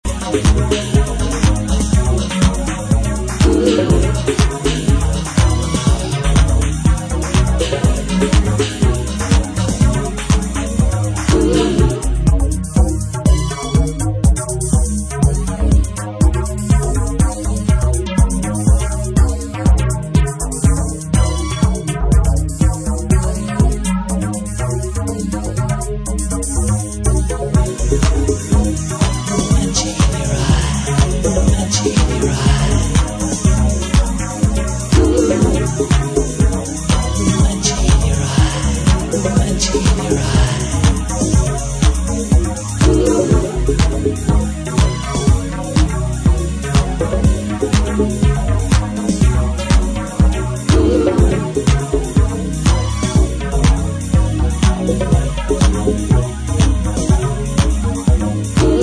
[90SHOUSE]